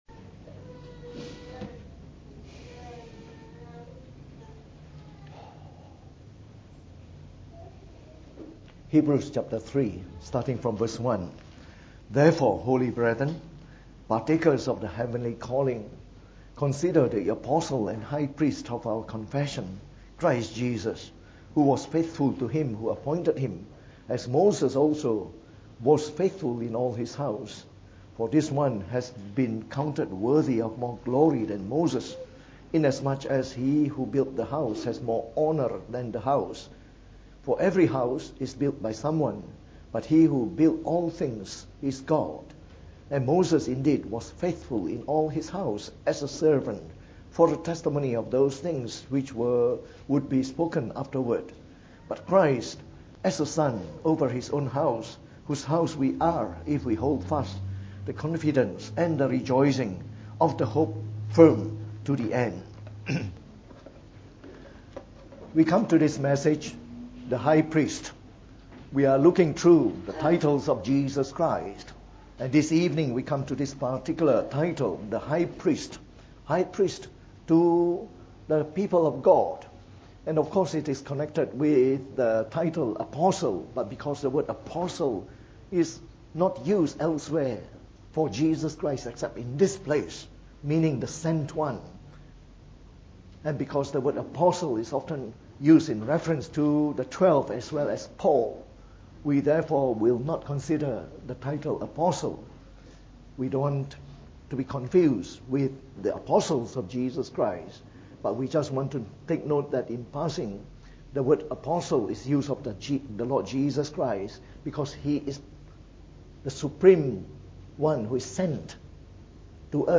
From our series on the Titles of Jesus Christ delivered in the Evening Service.